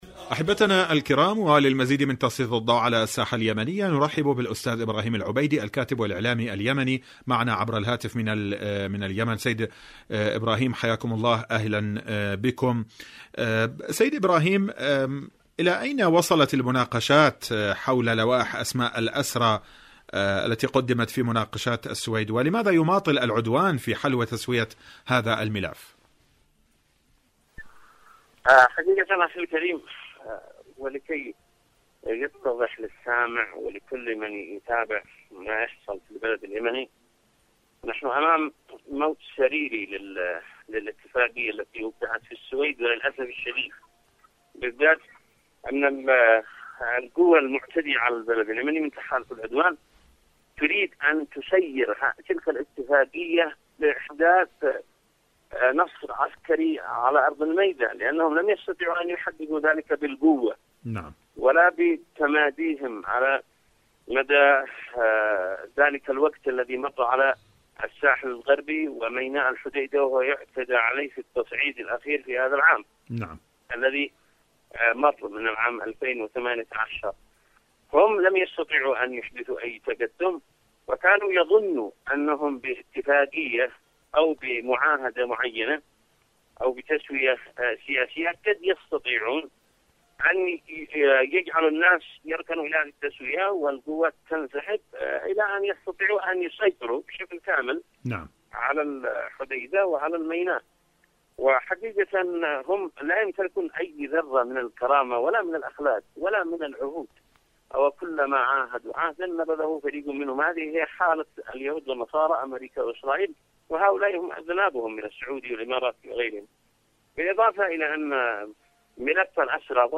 مقابلة إذاعية